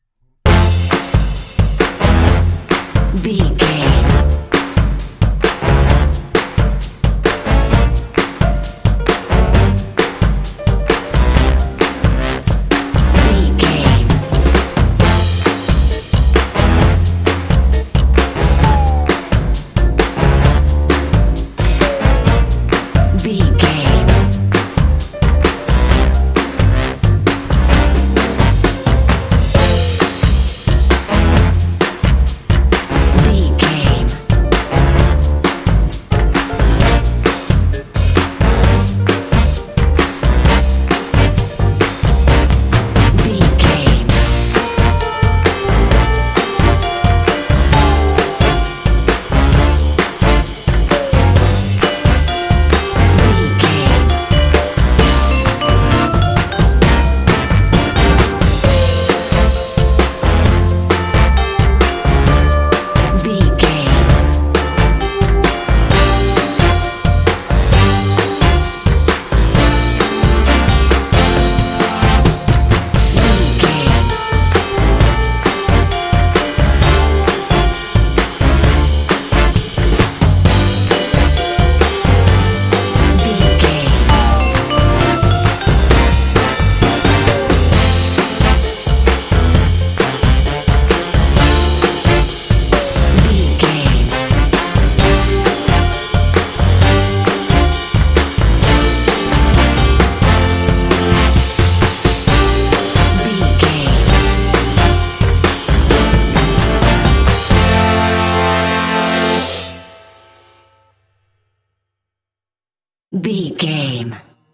Uplifting
Ionian/Major
happy
bouncy
groovy
drums
brass
electric guitar
bass guitar